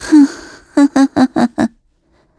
Gremory-Vox_Happy2_kr.wav